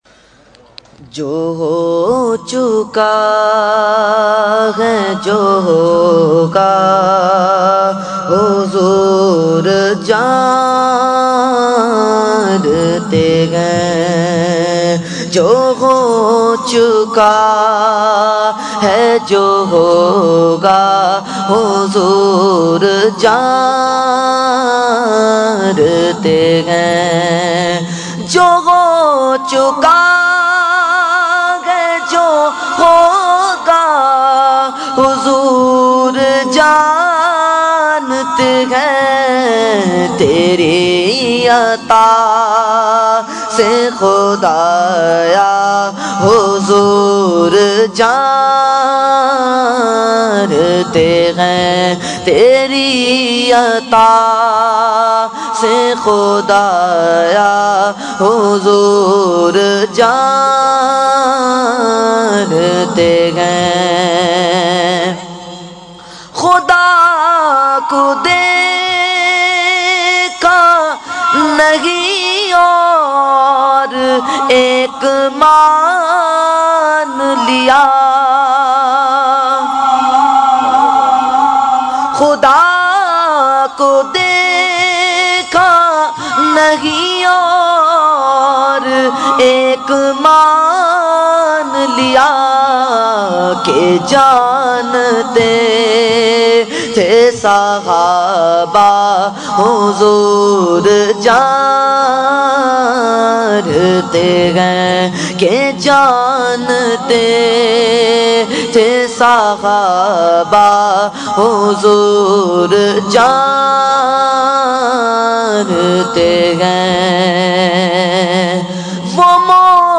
Category : Naat | Language : UrduEvent : Mehfil Milad 8 March 2013 North Nazimabad